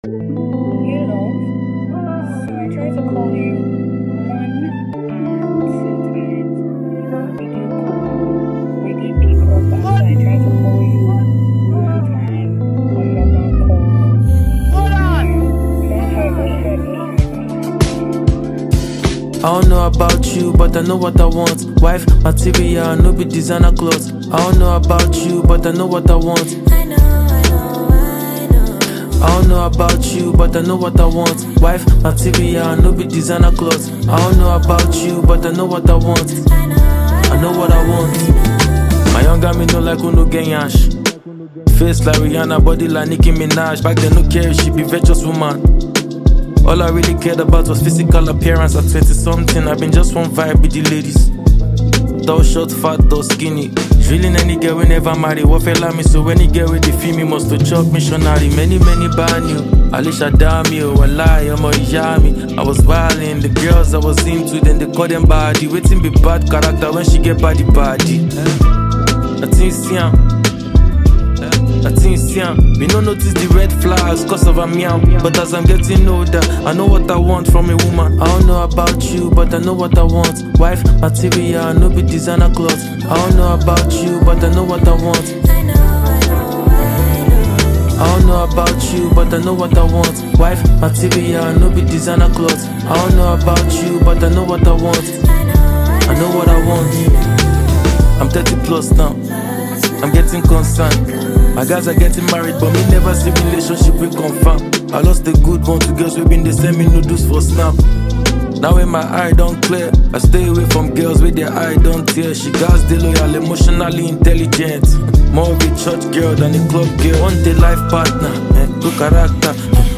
Nigerian rapper and songwriter
modern Nigerian hip-hop and Afro-fusion sounds